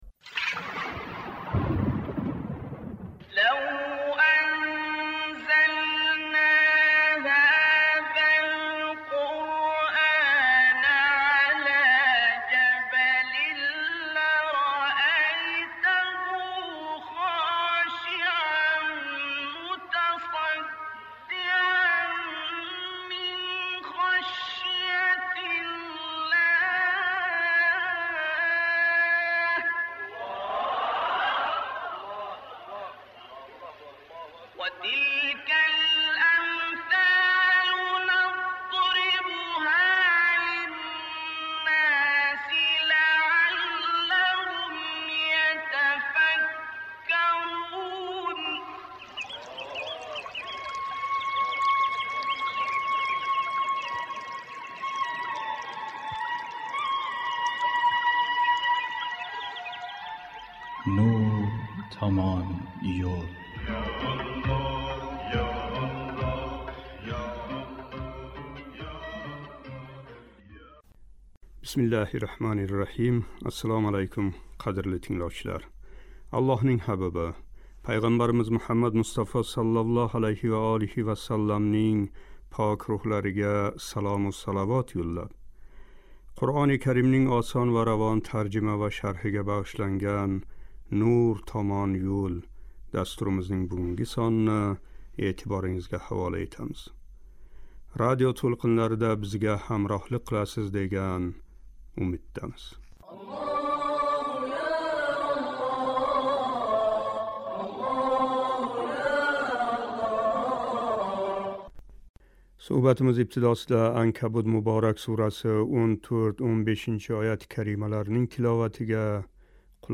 Суҳбатимиз ибтидосида “Анкабут” муборак сураси 14-15--ояти карималарининг тиловатига қулоқ тутамиз.